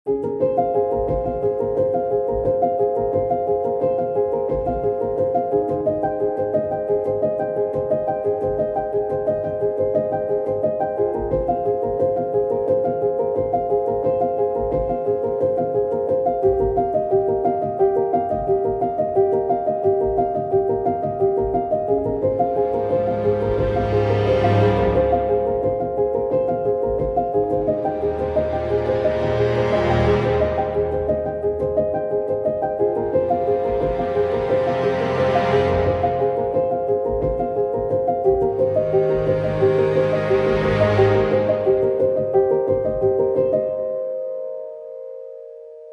podkład muzyczny - ,
podklad.mp3